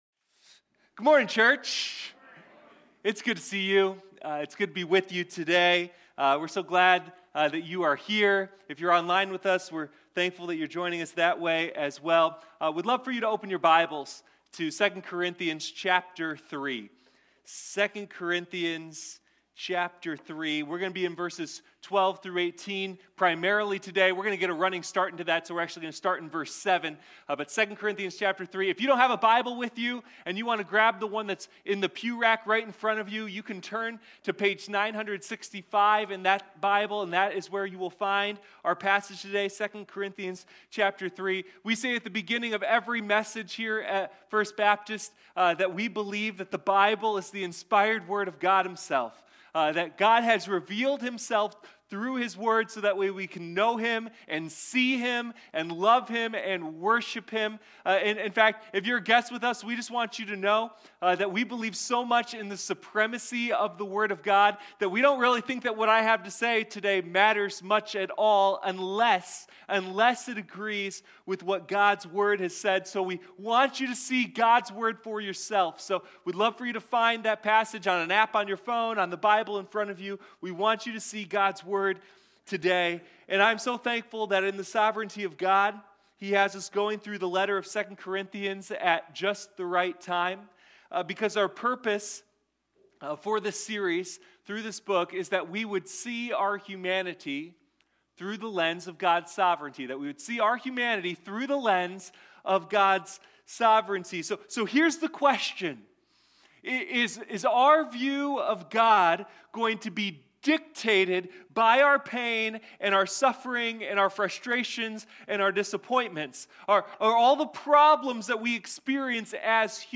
Sunday Morning God's Power in our weakness: 2 Corinthians